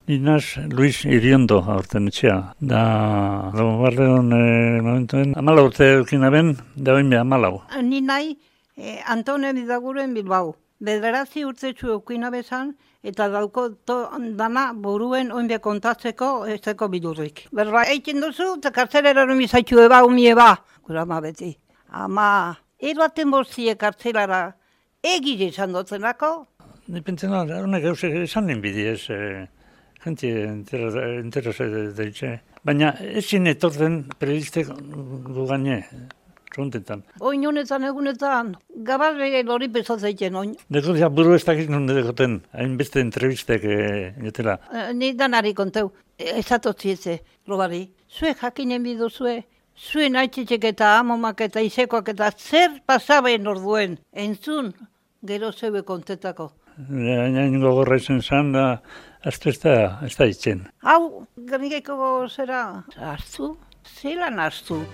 Gernikako bonbardaketaren lekukoak